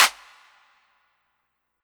Metro Claps [Matter].wav